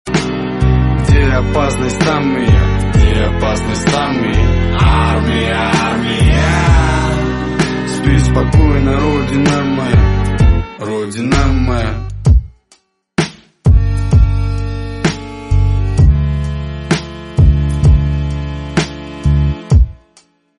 гитара
мужской голос
спокойные